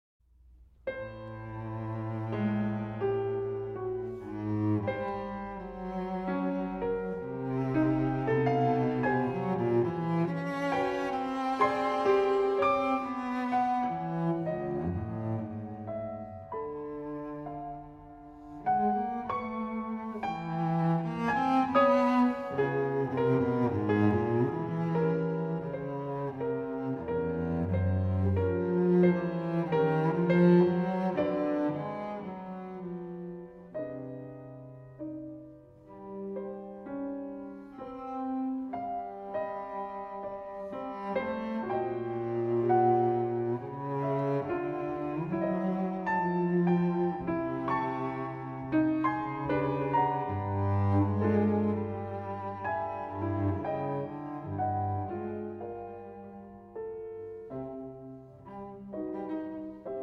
for violin, violoncello & piano